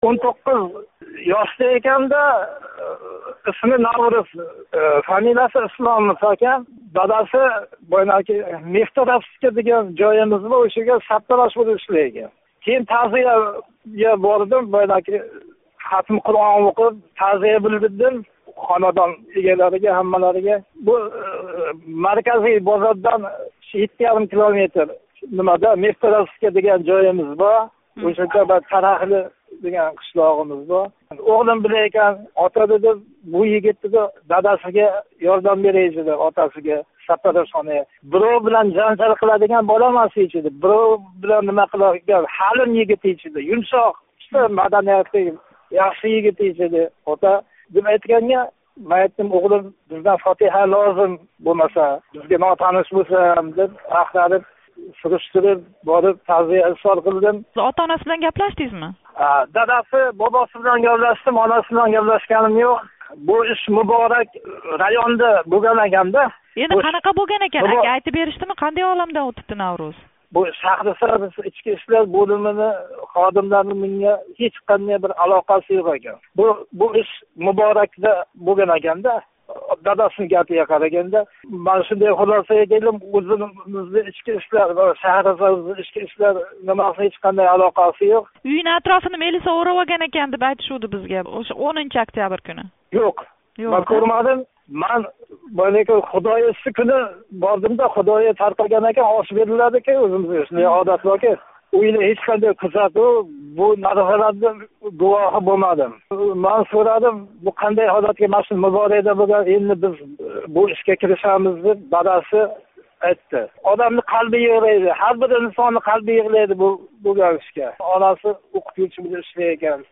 Шаҳрисабзлик тингловчи билан суҳбат.